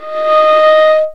Index of /90_sSampleCDs/Roland L-CD702/VOL-1/STR_Viola Solo/STR_Vla Harmonx